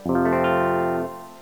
boot_sound.wav